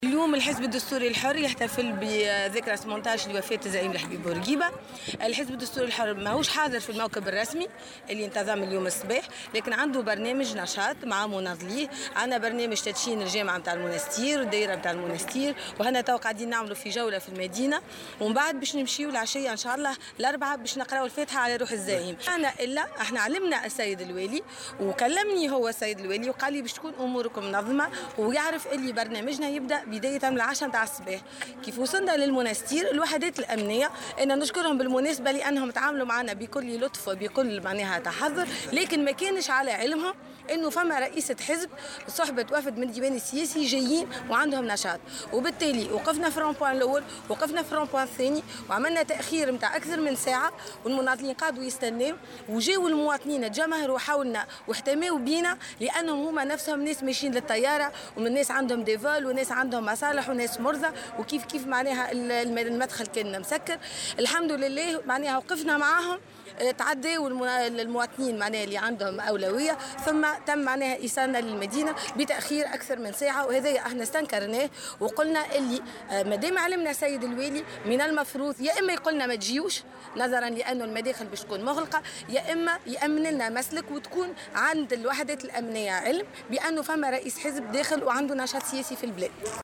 وأضافت في تصريح لمراسل "الجوهرة أف أم" بالجهة أنه تم تعطيل نشاط حزبها، بسبب غلق المنافذ المؤدية إلى وسط المدينة، على الرغم من الإعلام المسبق لوالي المنستير بنشاط حزبها بمناسبة إحياء ذكرى وفاة الزعيم الحبيب بورقيبة.